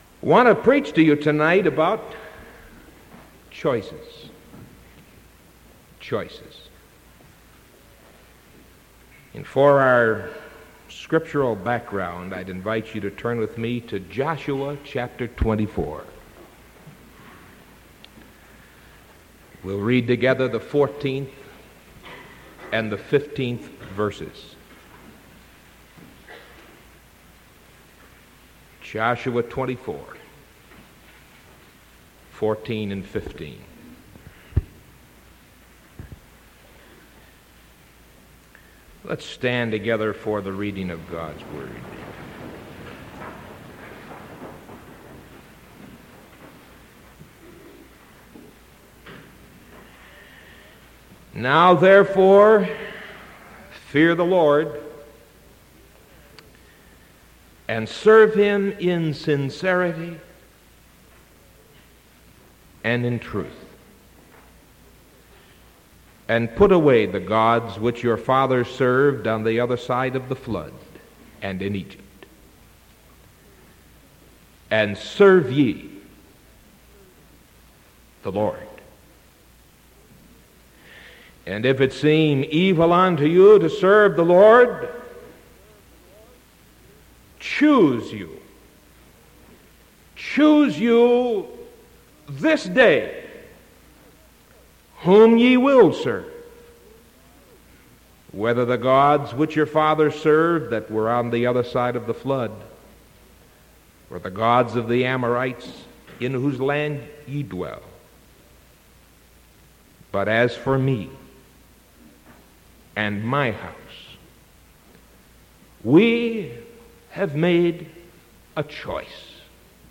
Sermon August 31st 1975 PM